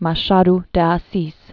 (mä-shäd dĭ ä-sēs), Joaquim María 1839-1908.